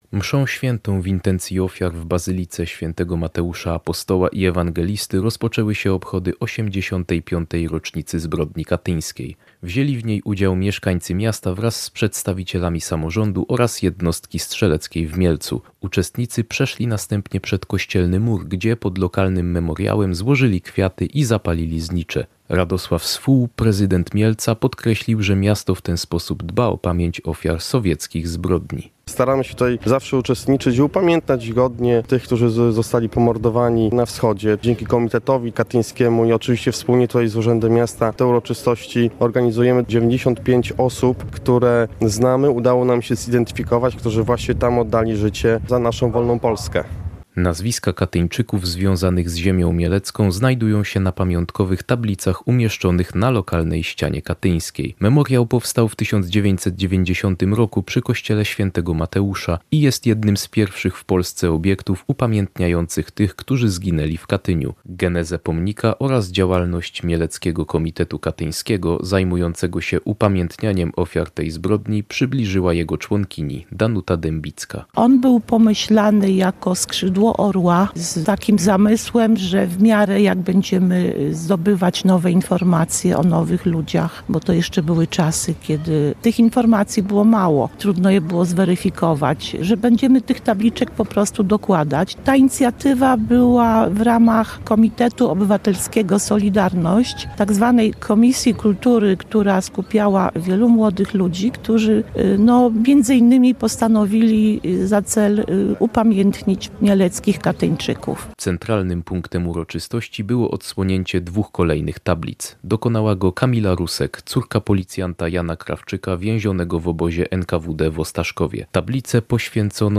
85. rocznicę zbrodni katyńskiej obchodzili dziś (13.04) mieszkańcy Mielca, którzy wraz z przedstawicielami samorządu i służb wzięli udział we mszy świętej w Bazylice pw. św. Mateusza Apostoła i Ewangelisty, po czym złożyli kwiaty i zapalili znicze pod mielecką Ścianą Katyńską.
– mówił Radosław Swół, prezydent Mielca.